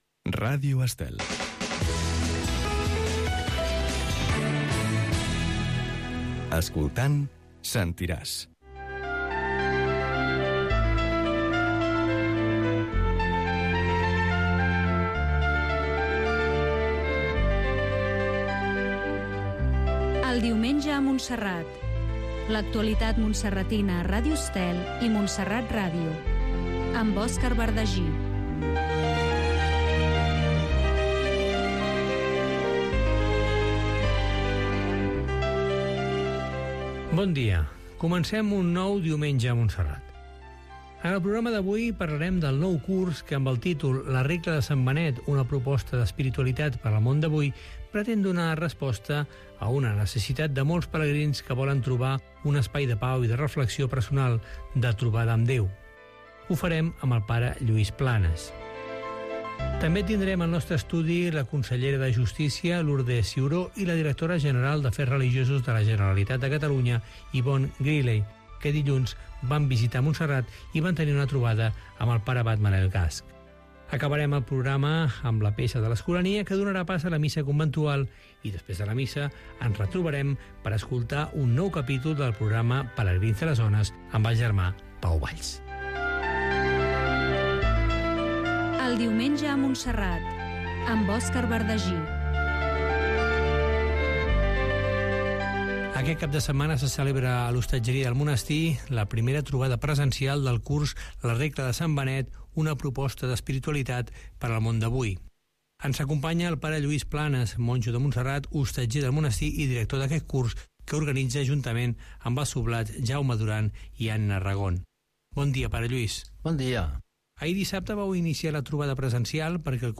Indicatiu de l'emissora, careta del programa, sumari